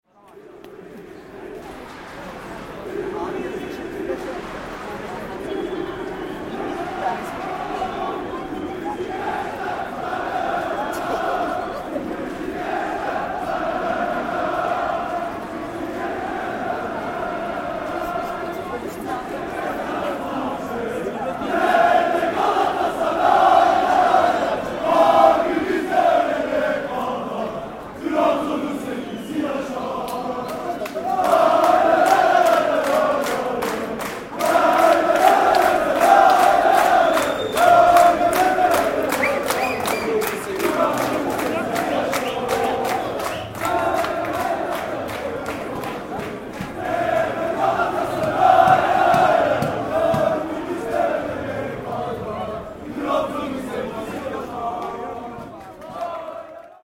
Last week there was a game between Galatasaray (I guess) and Trabzonspor. The visiting team supporters, probably a few hundred of them, wearing blue and burgundy, walked through Beyoğlu chanting and clapping their hands.
Listen to the fans pass by: